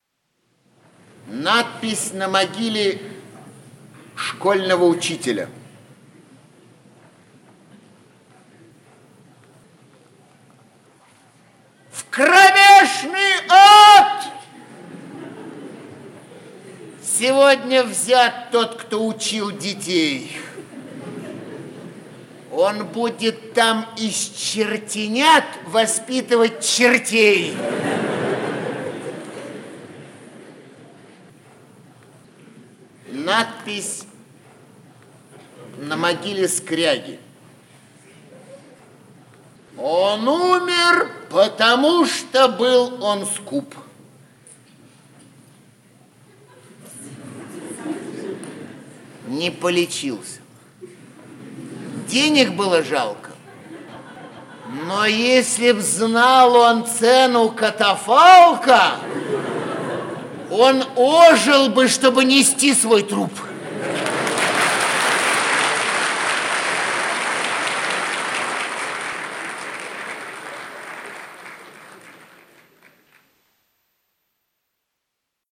Аудиокнига Концерт Игоря Ильинского | Библиотека аудиокниг
Aудиокнига Концерт Игоря Ильинского Автор Игорь Владимирович Ильинский Читает аудиокнигу Игорь Владимирович Ильинский.